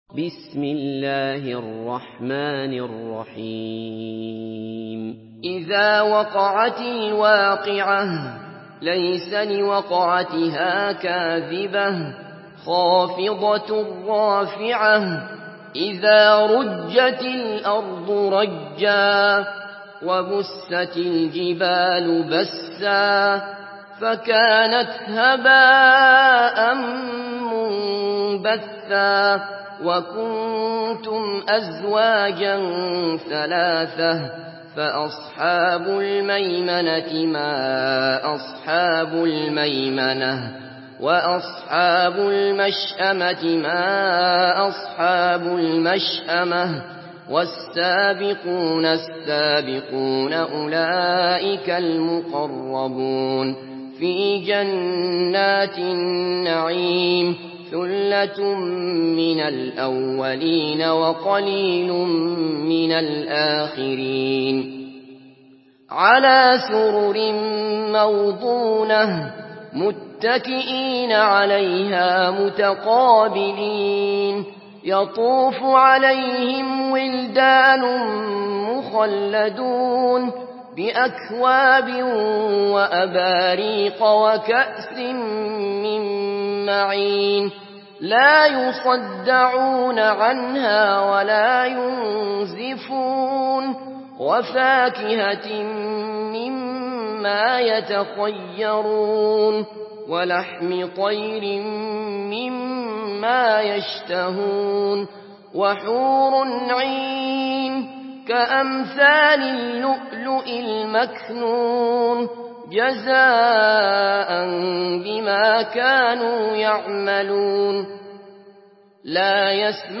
Une récitation touchante et belle des versets coraniques par la narration Hafs An Asim.